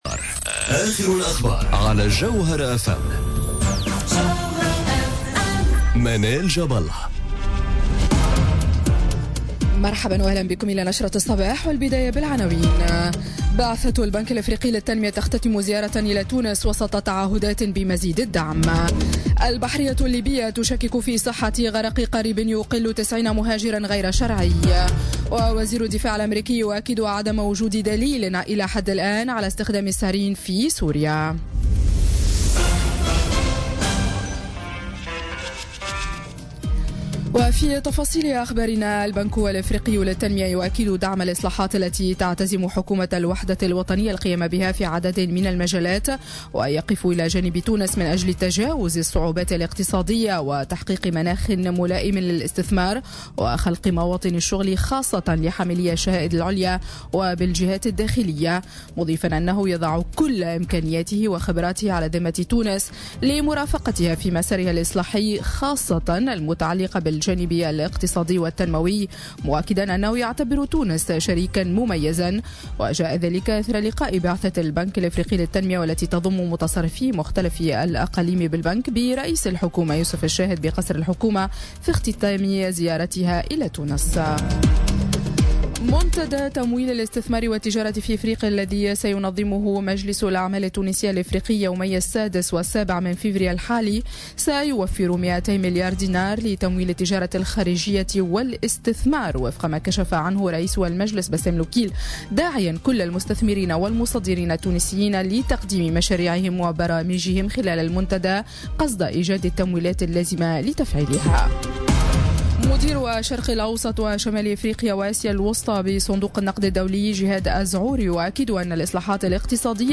نشرة أخبار السابعة صباحا ليوم الأحد 3 فيفري 2018